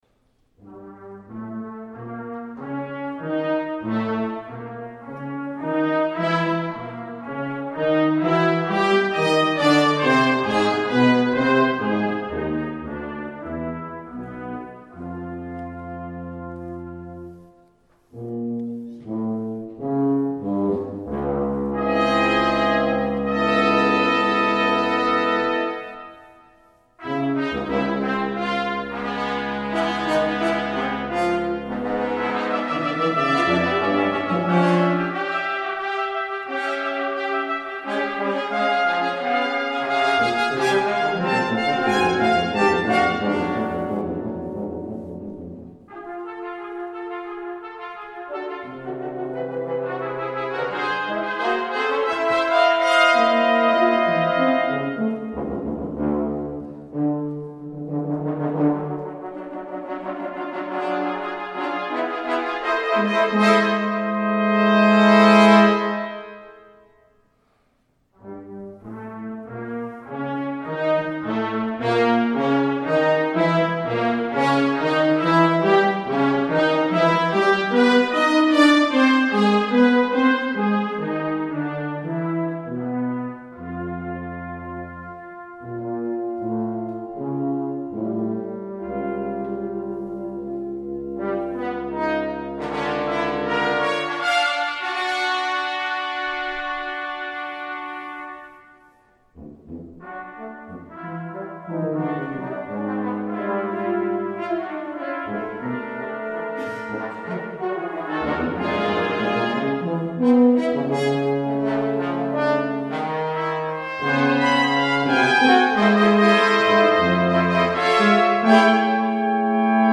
Brass Quintet (10′)